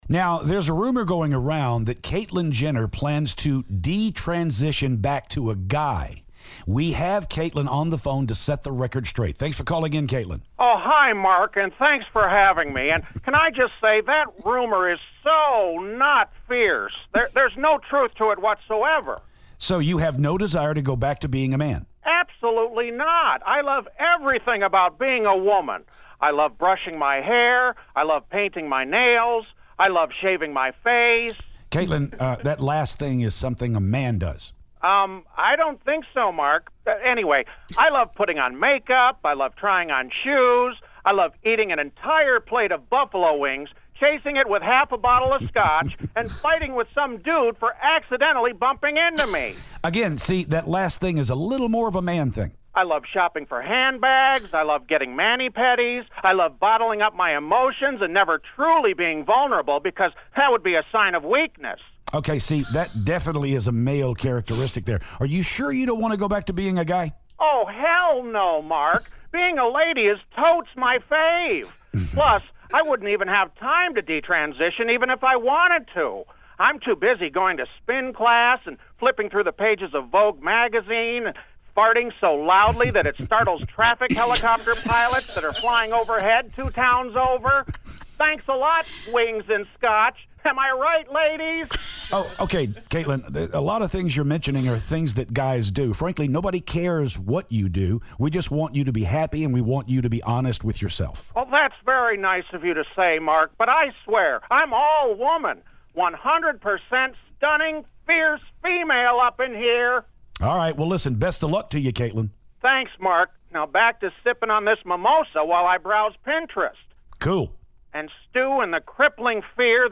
Caitlyn Jenner Phoner
Caitlyn Jenner calls to talk about possibly going back to being a guy.